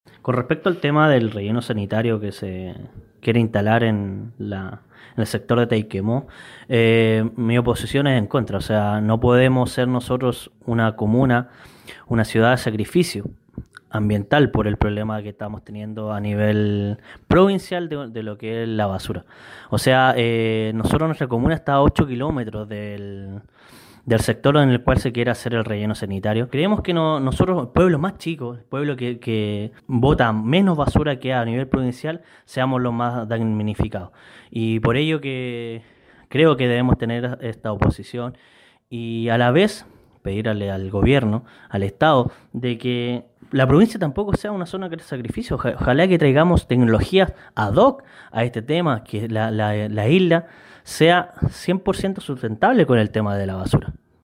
En tanto, el concejal Danilo Gómez, expresó también su negativa a esta propuesta intercomunal de un sitio para la disposición de la basura.